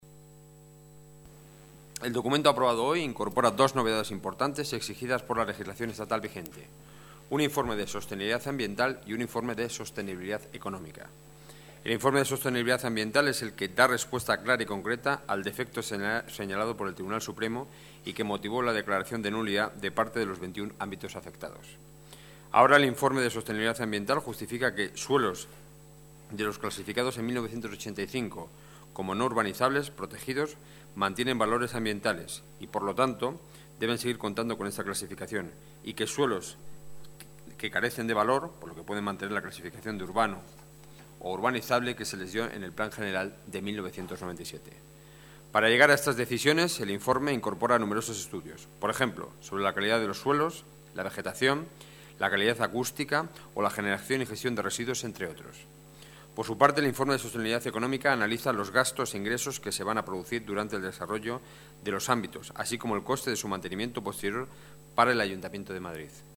Nueva ventana:Declaraciones de Enrique Núñez, portavoz del Gobierno municipal